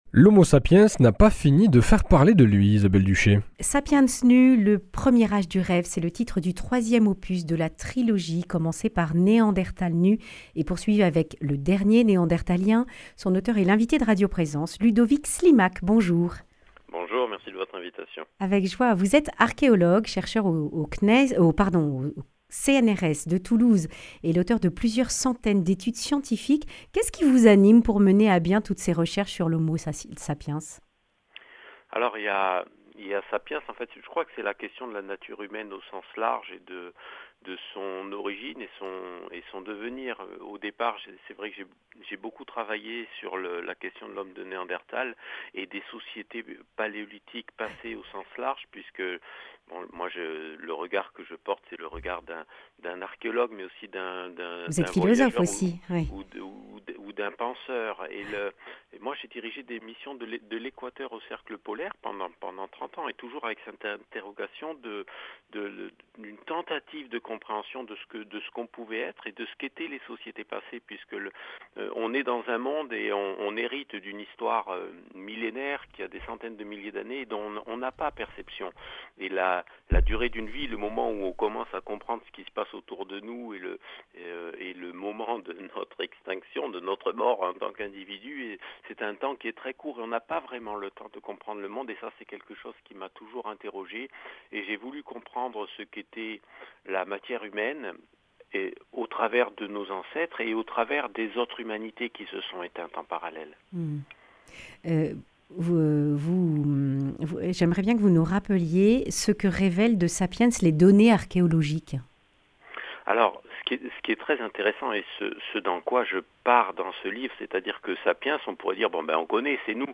Le grand entretien
Journaliste